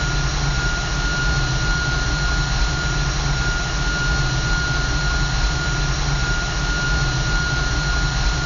Edit sounds to remove noise and make them loop better